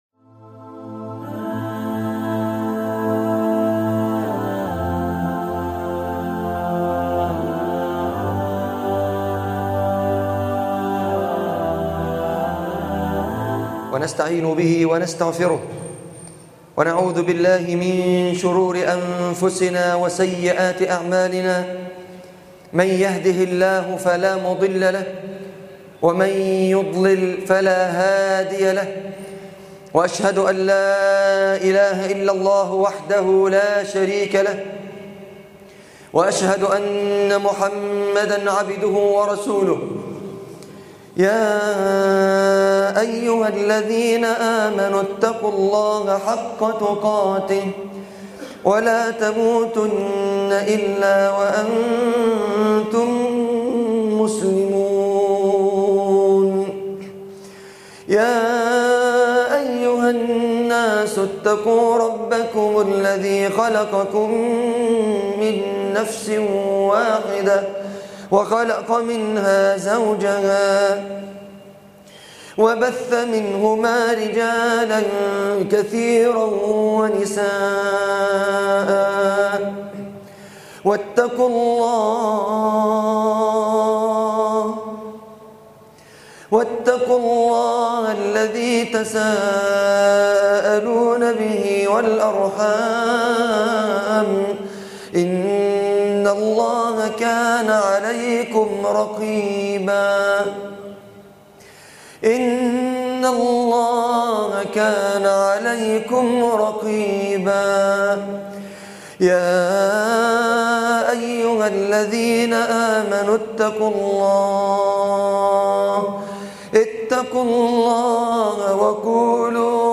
كيف تترك المعاصي خطبة الجمعة